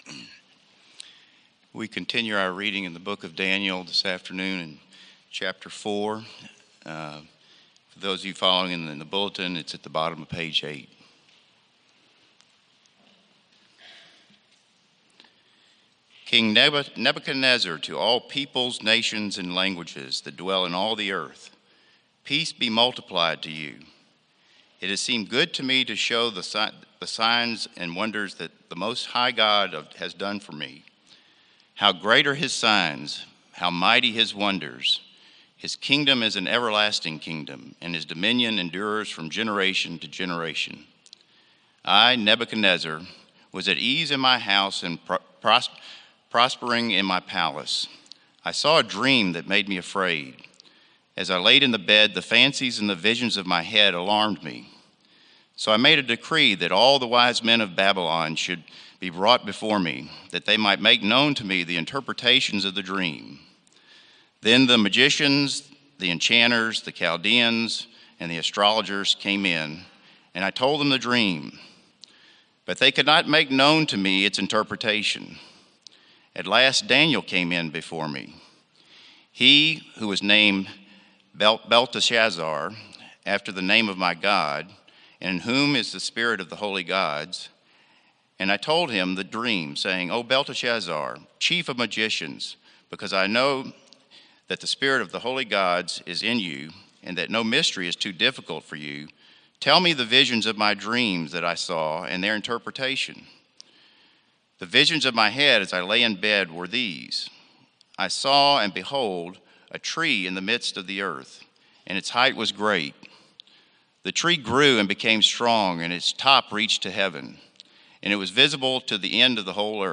Sermons - Trinity Presbyterian Church (PCA)